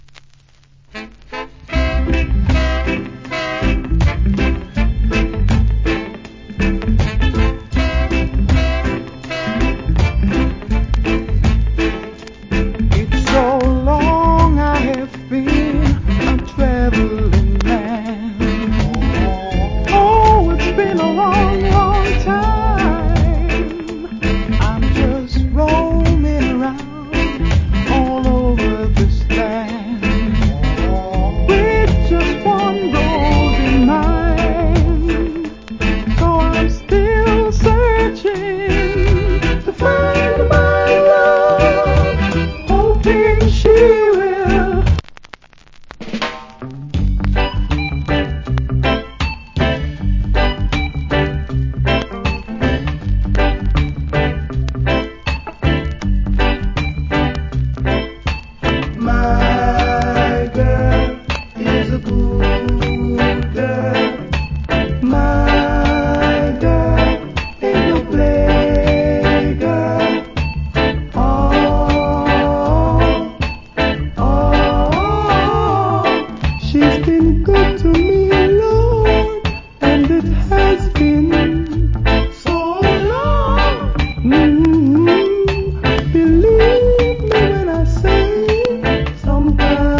Rock Steady Vocal.